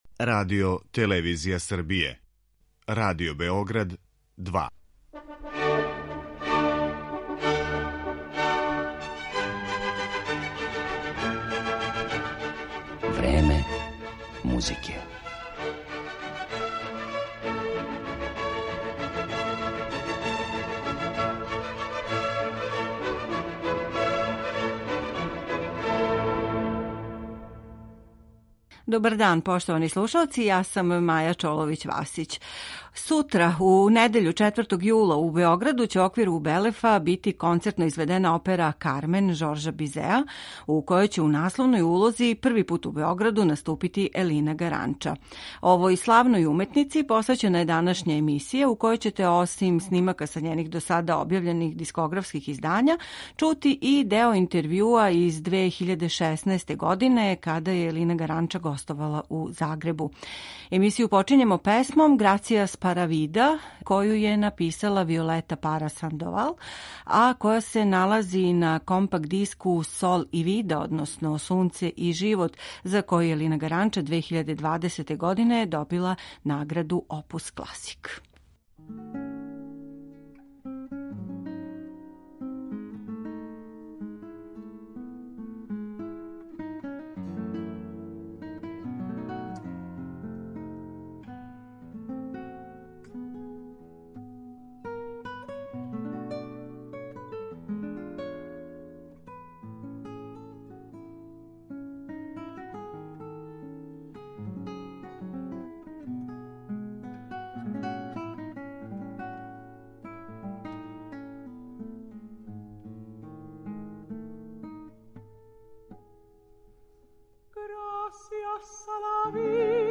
Њен глас је аутентичним изразом оживео низ ликова из дела Моцарта, Вердија, Доницетија, Бизеа и других оперских стваралаца, али се на њеном репертоару налазе и духовне композиције, као и вокална лирика. Део овог богатог извођачког опуса представићемо вам у данашњој емисији, а бићете у прилици да чујете и кратки интервју који је снимљен у Загребу 2016. године.